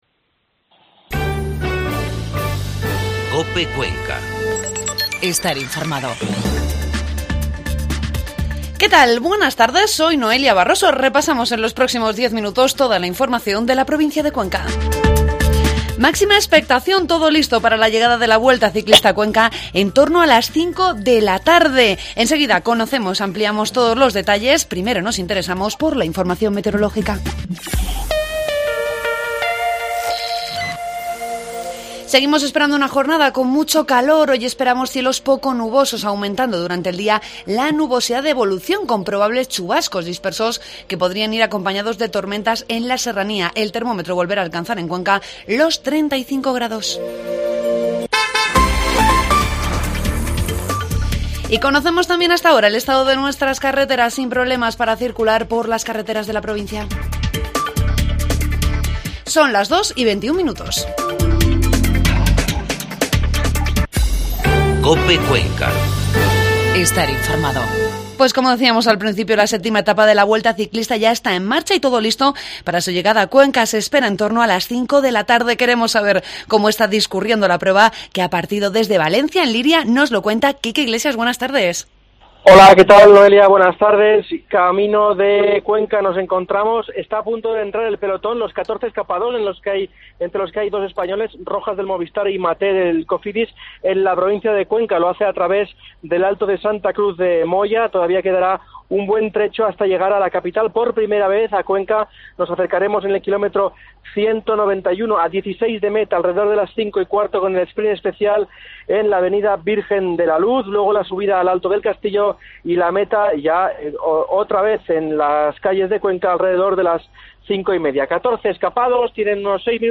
Informativo Cope Cuenca